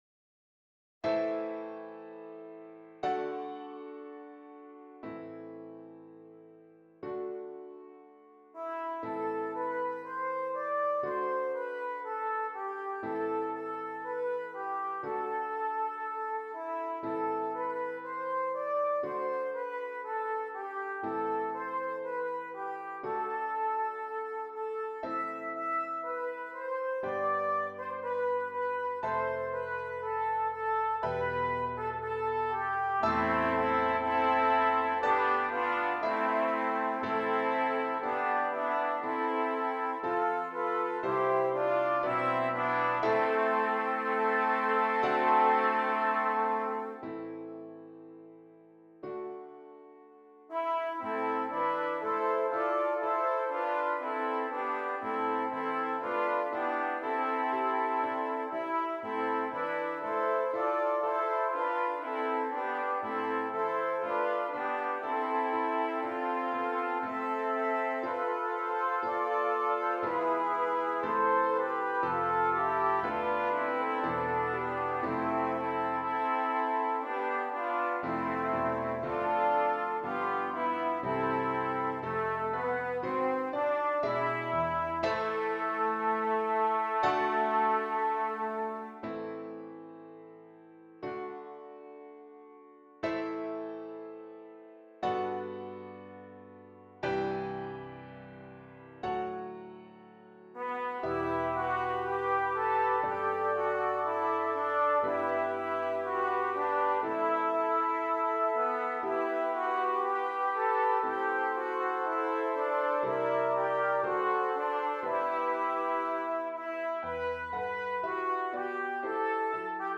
3 Trumpets and Keyboard
Traditional